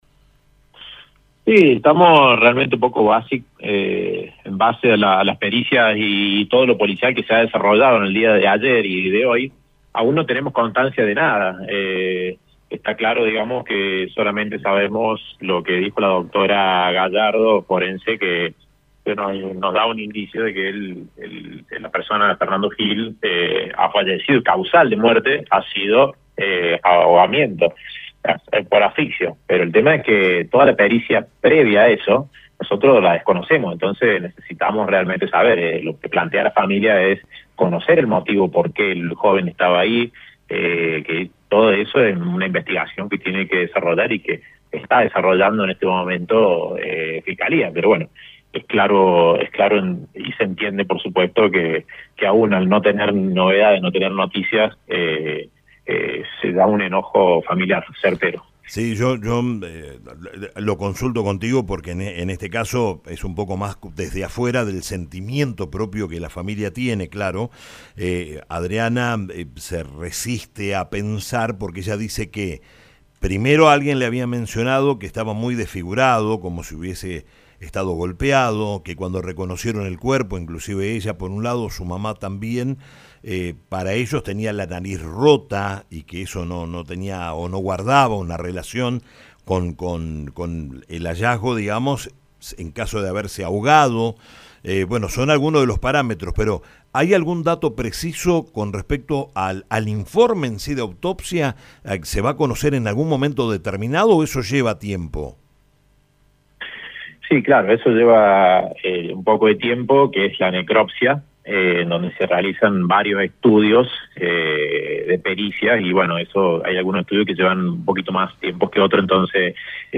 🎙 Entrevista